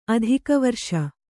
adhikavarṣa